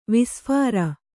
♪ visphāra